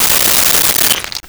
Glass Break 02
Glass Break 02.wav